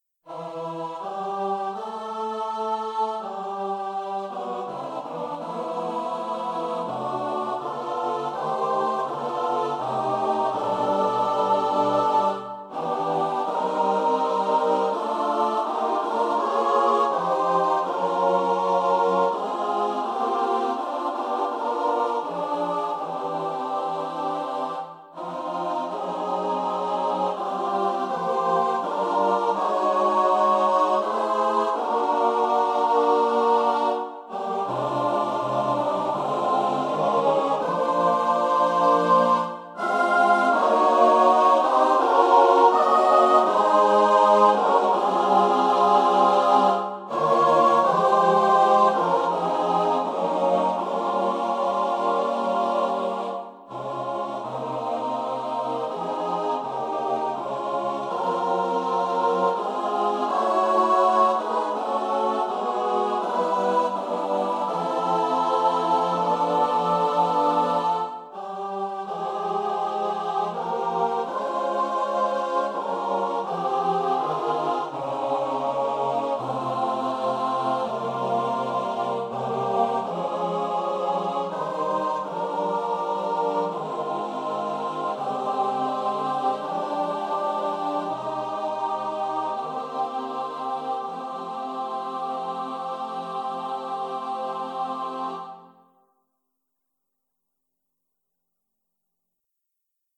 for SATB a cappella